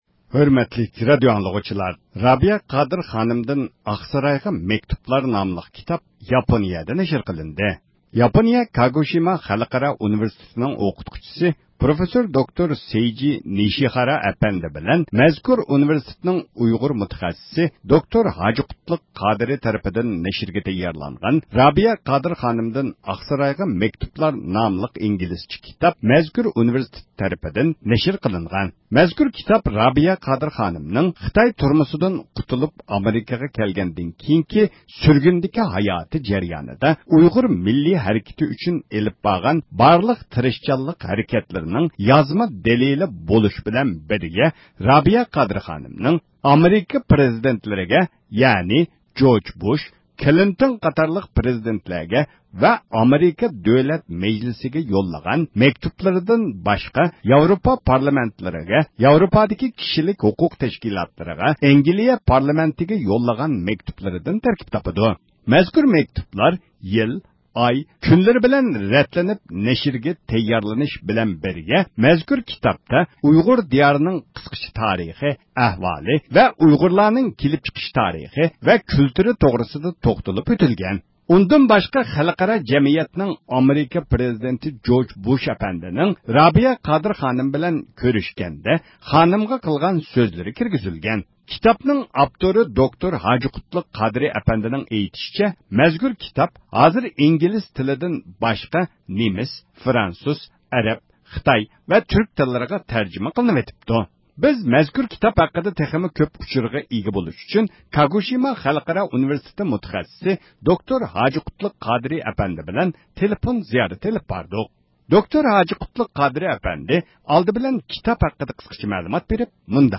تېلېفون زىيارىتى ئېلىپ باردۇق.